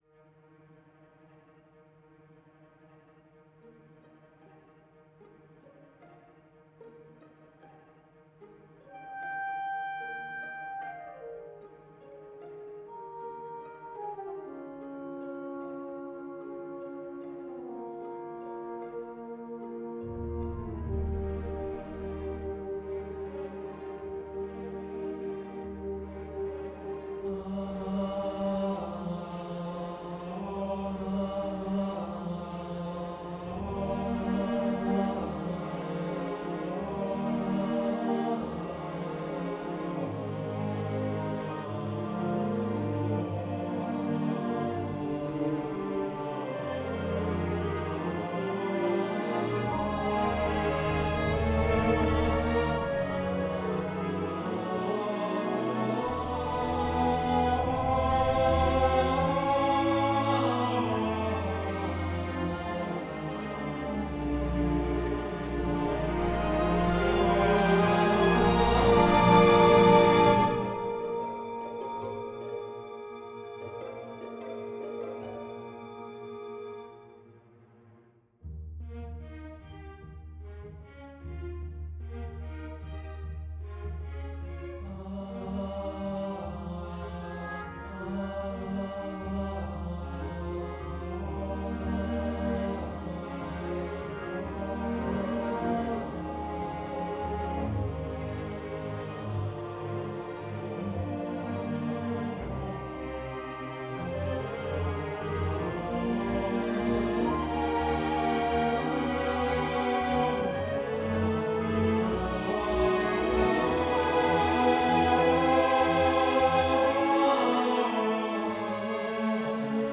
Orchestra audio (con voci sintetiche)